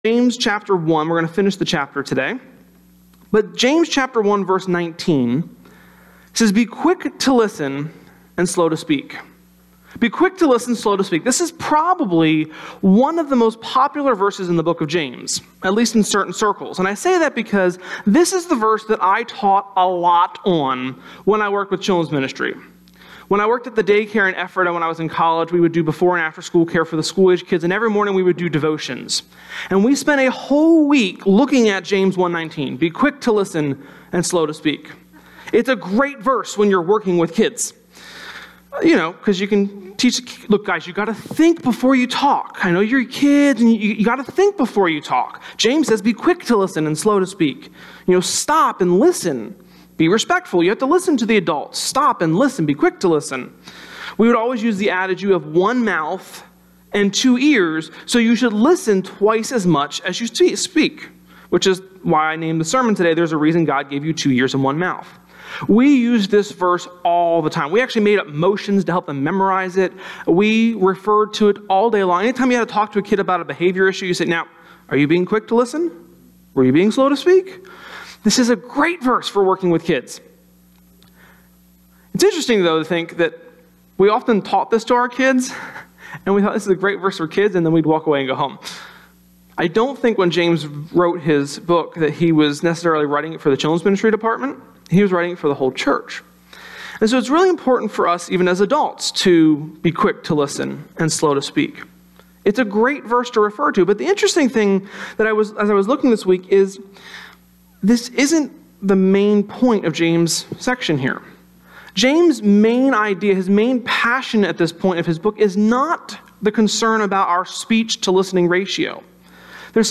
Sermon-4.30.17.mp3